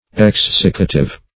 Exsiccative \Ex*sic"ca*tive\, a. Tending to make dry; having the power of drying.